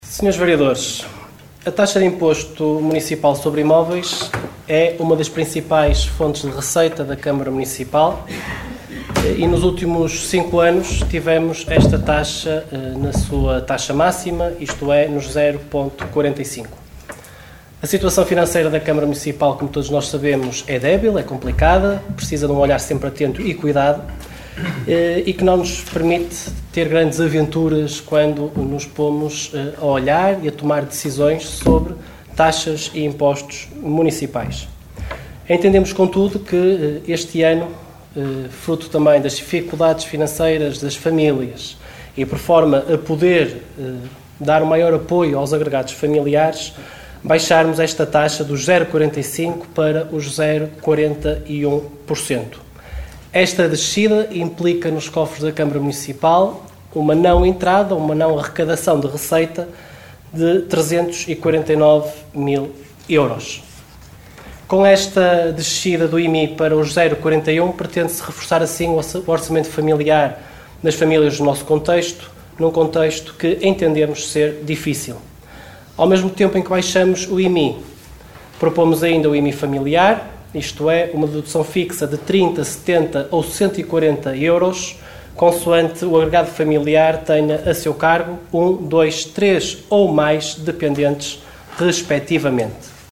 Reuniao Camarararia 30 Nov 2023